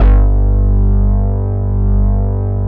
28 MOOG BASS.wav